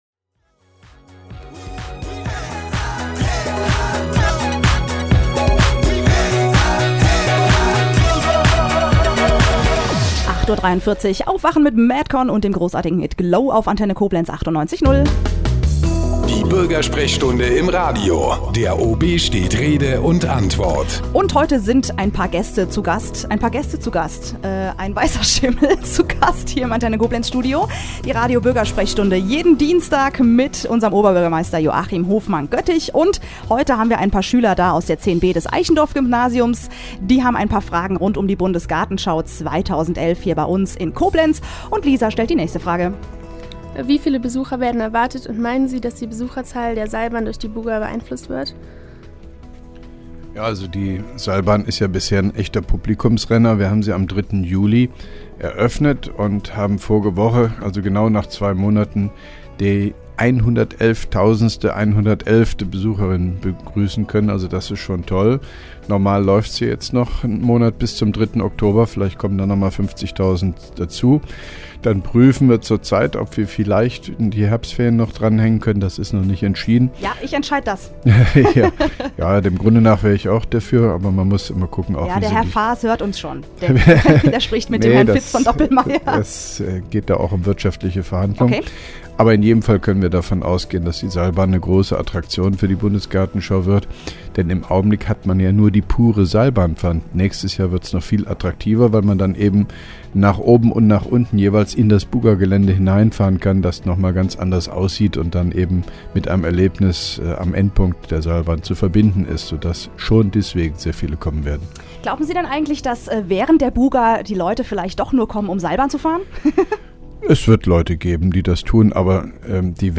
(3) Koblenzer OB Radio-Bürgersprechstunde 07.09.2010